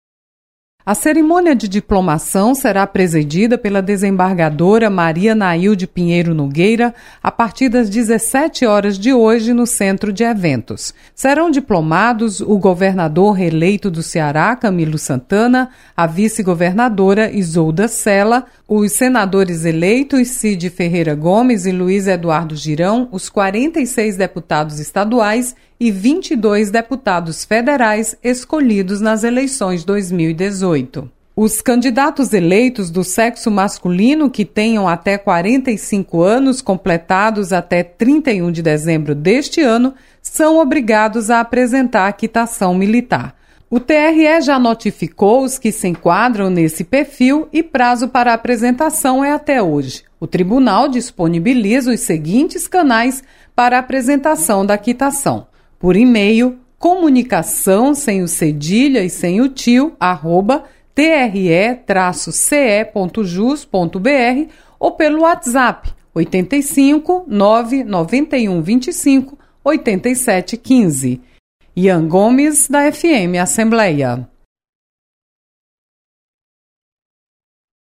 Audiências discutem sobre políticas públicas e otimização de aplicativos de comunicação. Repórter